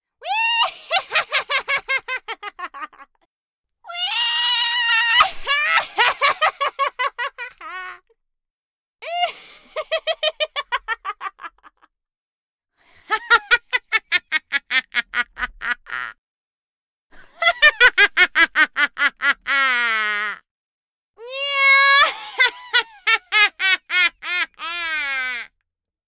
Witch laugh
cackle dark evil female insane joker laugh magic sound effect free sound royalty free Funny